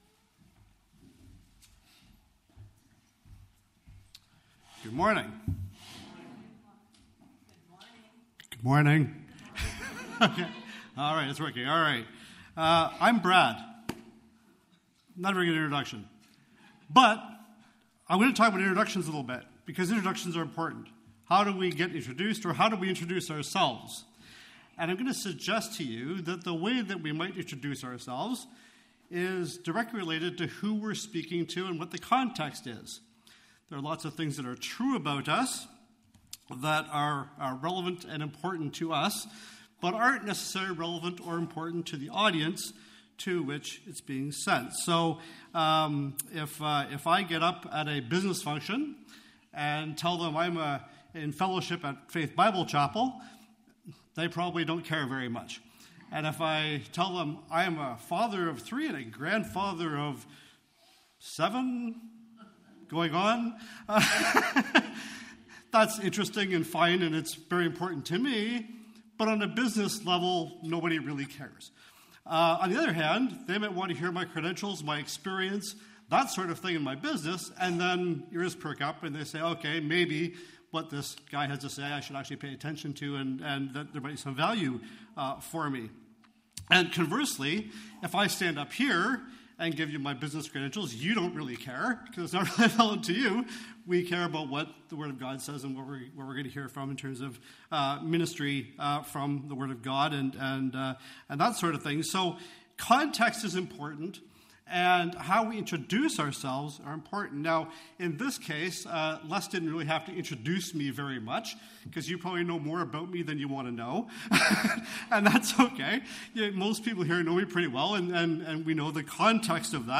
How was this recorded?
May 5, 2024 The Apostle Paul’s Introduction Passage: Various Service Type: Family Bible Hour A survey of the way Paul described himself in some of his epistles.